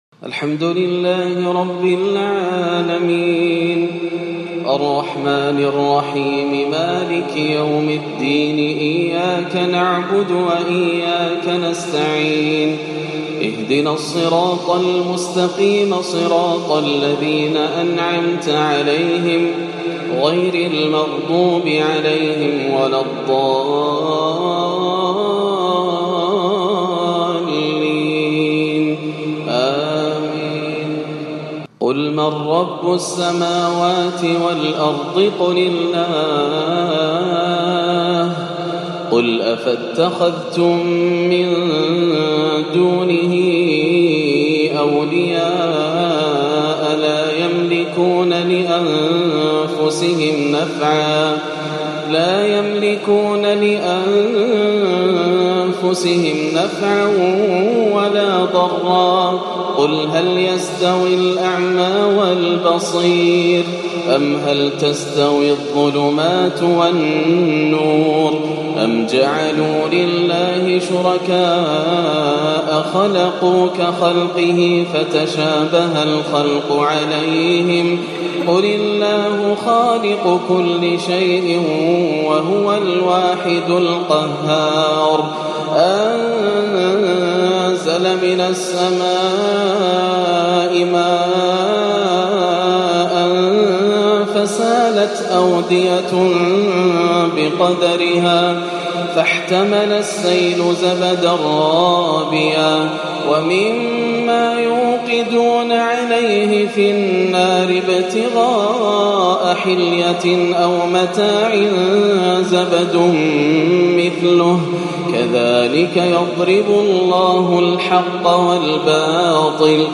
(أَلَا بِذِكْرِ اللَّهِ تَطْمَئِن الْقُلُوبُ) الحياة سعادة بالقرب من الله - تلاوة خاشعة جداً - 15-4 > عام 1437 > الفروض - تلاوات ياسر الدوسري